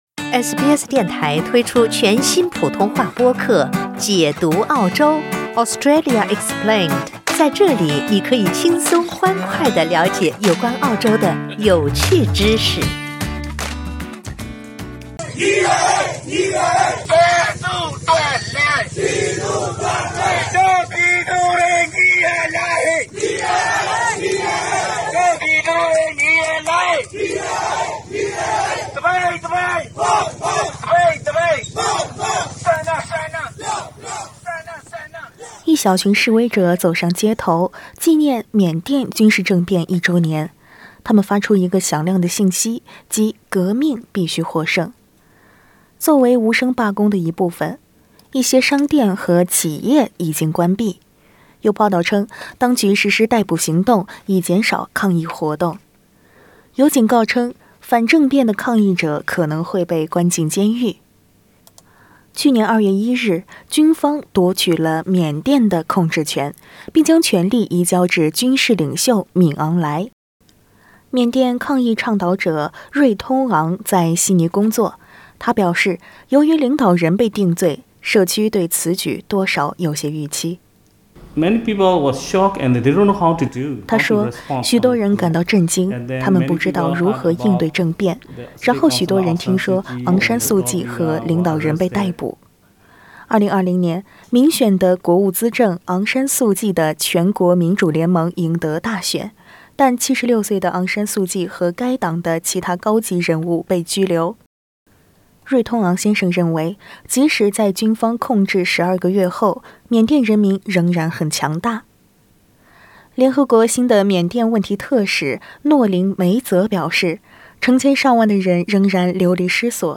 在这个一周年纪念日，缅甸人权网络（Burma Human Rights network）发布了一份报告，公布了1500多名平民被杀害的证据。（请点击音频收听采访）